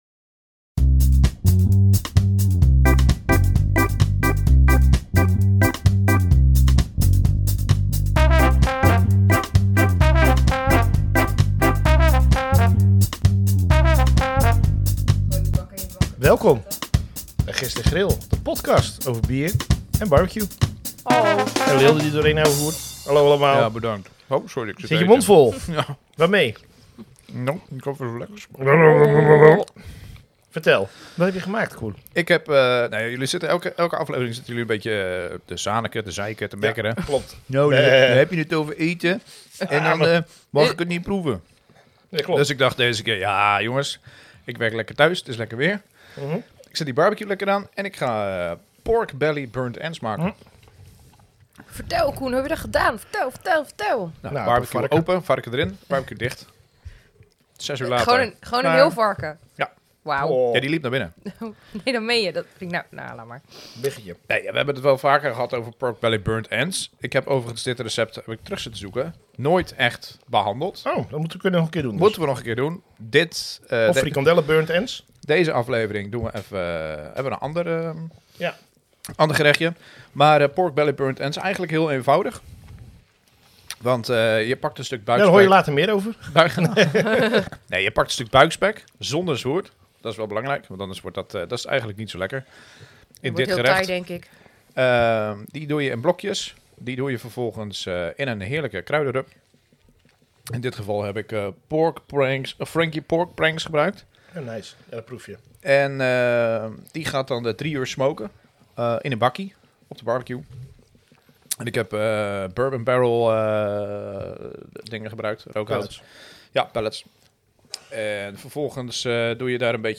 Gist en Grill de Podcast is een Podcast over Bier en BBQ. 3 Amateurs die ook wat te vertellen hebben.